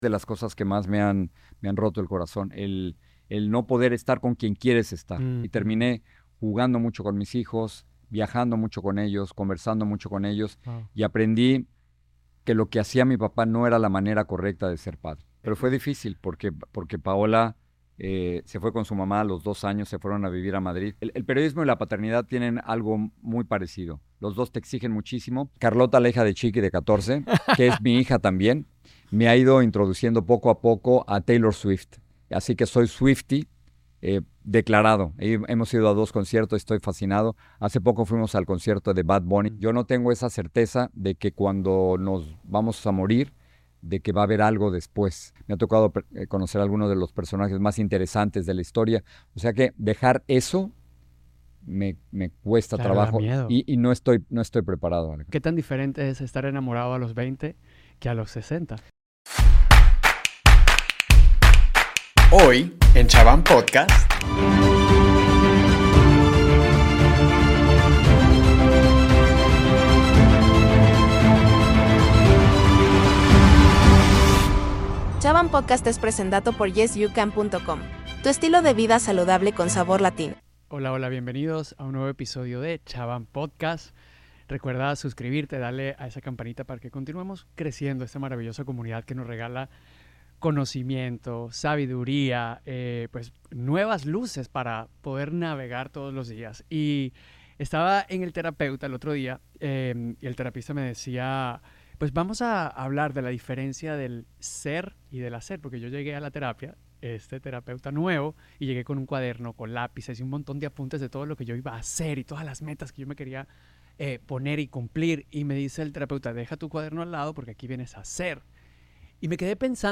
Con una trayectoria impresionante y una carrera que abarca décadas, Jorge nos comparte detalles no solo sobre su vida profesional, sino también sobre su vida personal, en una conversación que promete ser tan profunda como inspiradora. A lo largo de este episodio, Jorge Ramos nos lleva a través de su notable trayectoria en el mundo del periodismo. Con su característico estilo franco y directo, nos relata algunos de los momentos más importantes y desafiantes de su carrera.